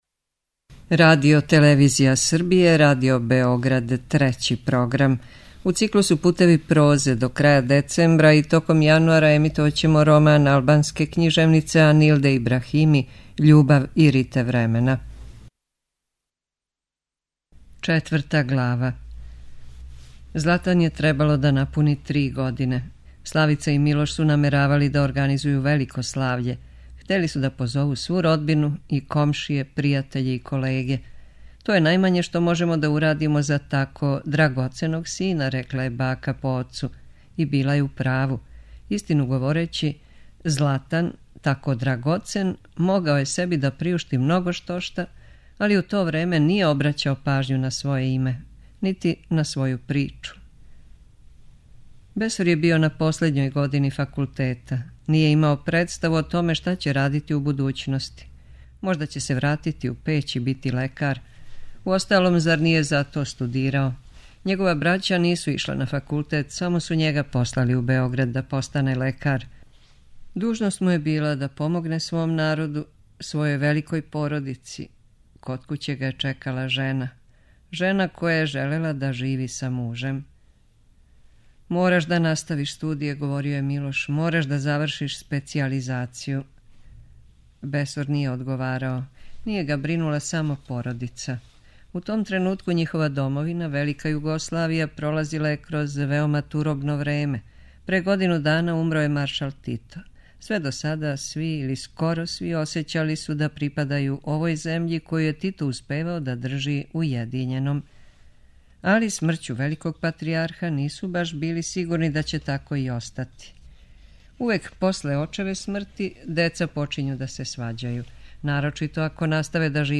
преузми : 8.07 MB Књига за слушање Autor: Трећи програм Циклус „Књига за слушање” на програму је сваког дана, од 23.45 сати.